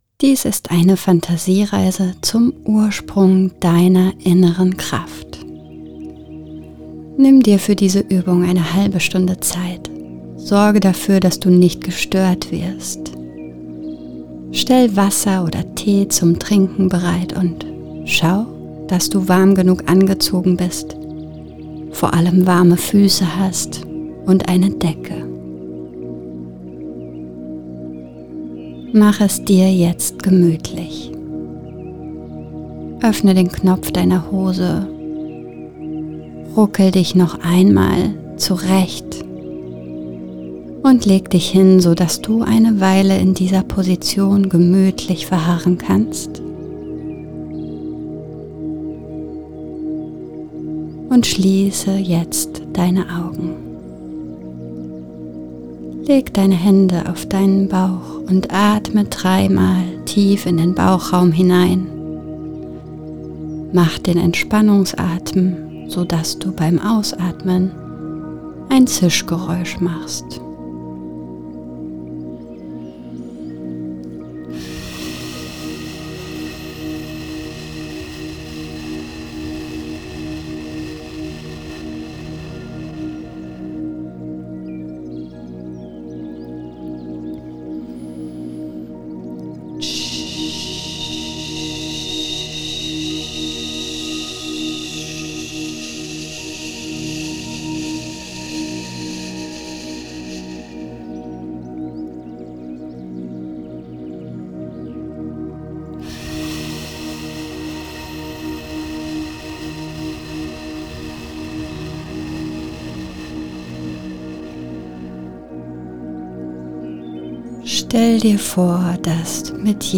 Inneres-Kind-Der-erste-Kontakt-MEDITATION-1.mp3